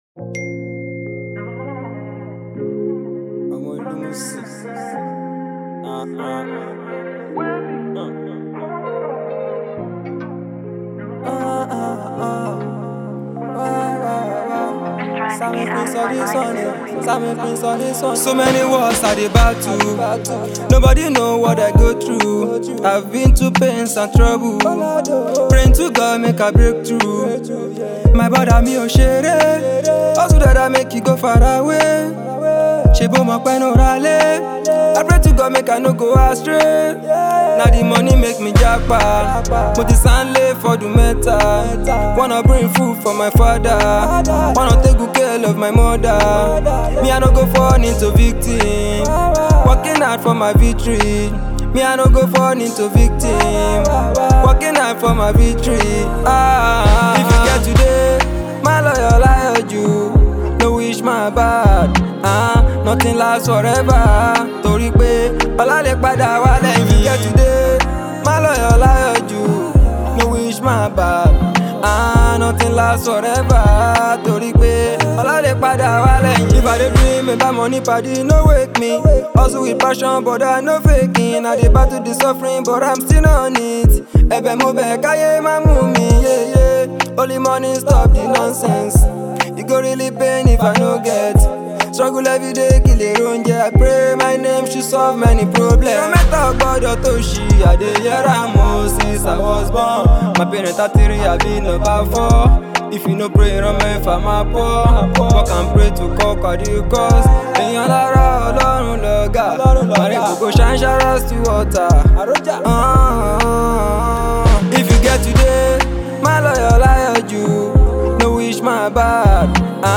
Afrobeat and contemporary music
infectious beats